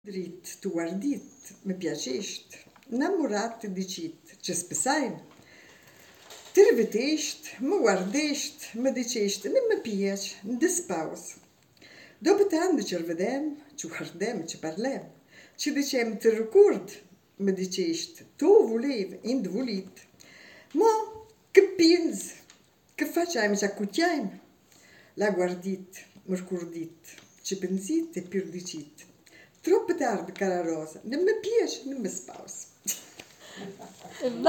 Il gruppo che recita le poesie in dialetto e che canta le due canzoni è composto da